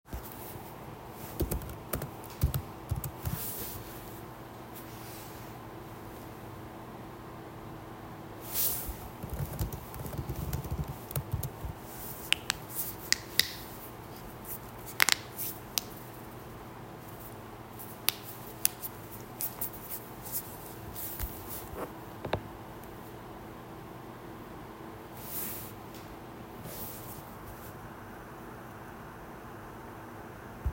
the air unit, my keyboard, cracking my knuckles
Field-Recording-2.m4a